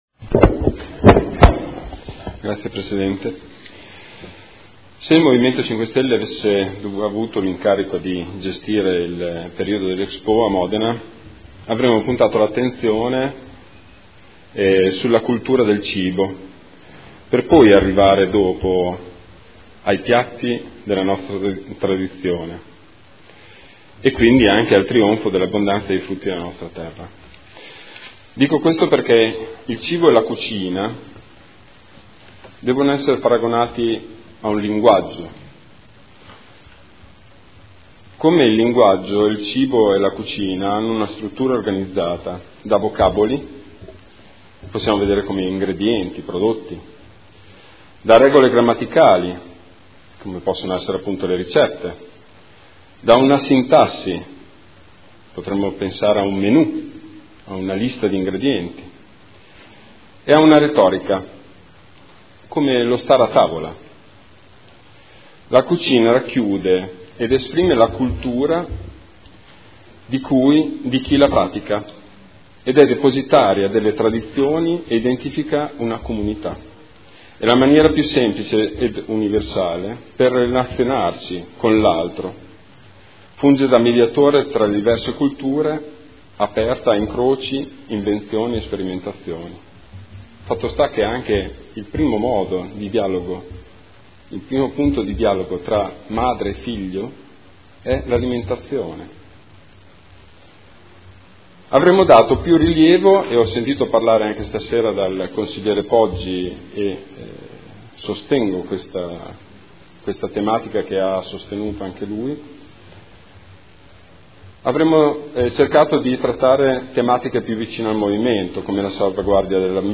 Marco Bortolotti — Sito Audio Consiglio Comunale
Seduta del 09/02/2015. Dibattito sugli ordini del giorno/mozioni inerenti l'expo 2015